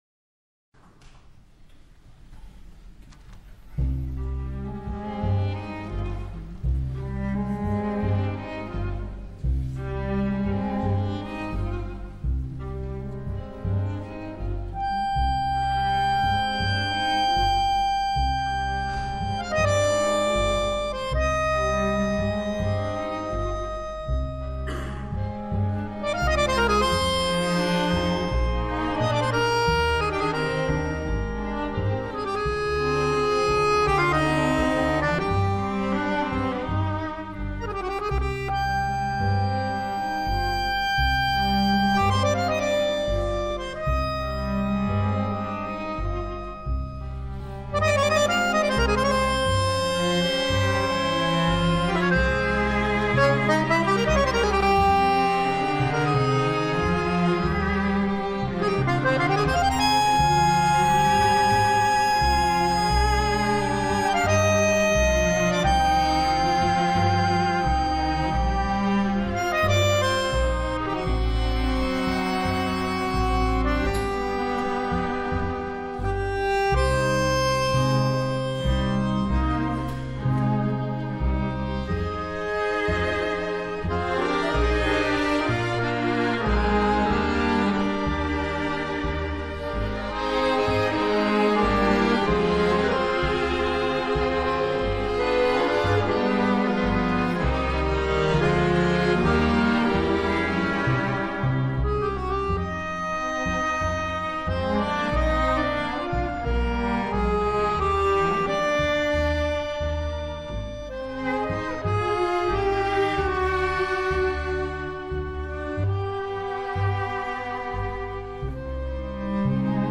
solo violin